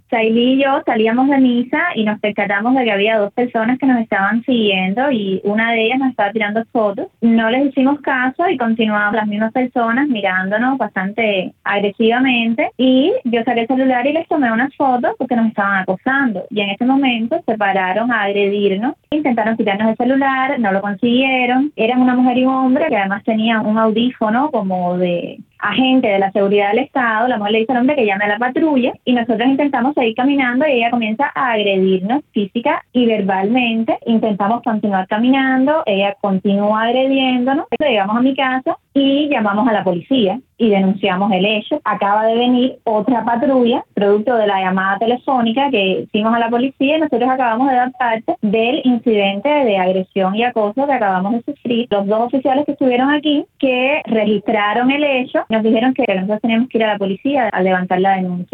Declaraciones de Rosa María Payá